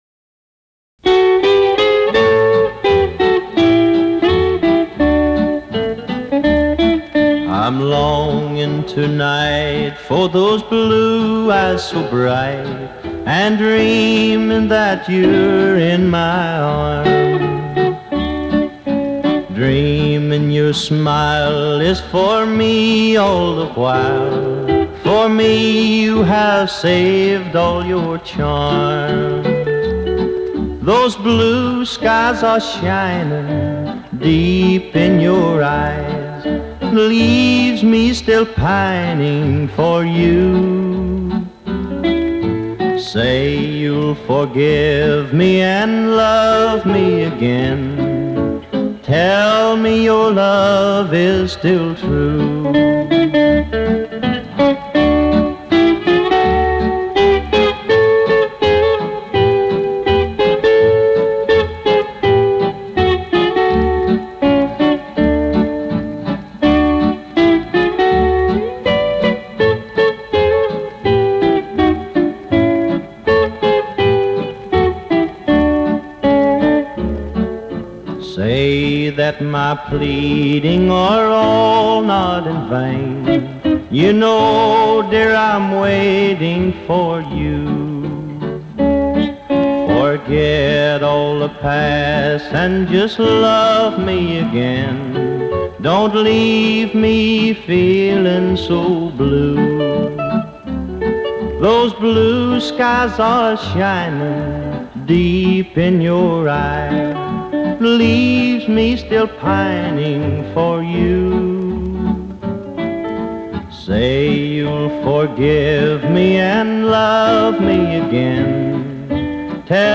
With the start of Rockabilly heard within.